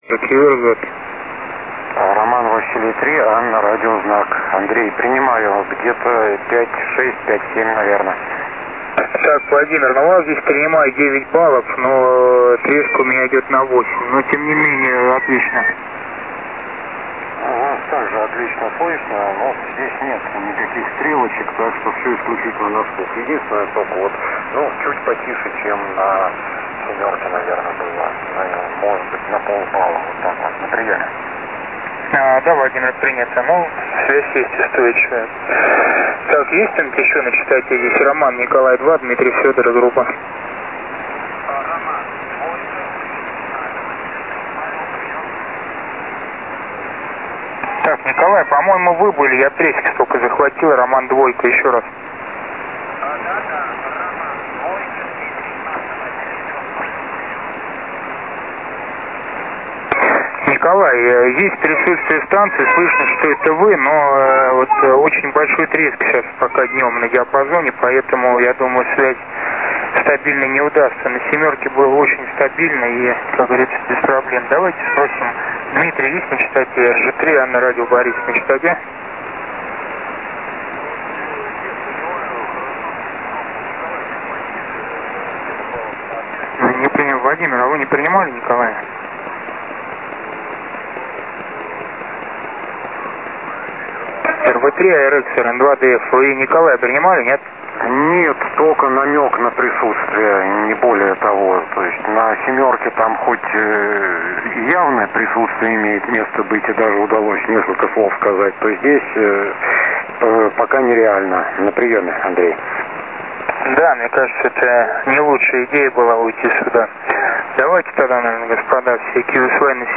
Группа на 80-ке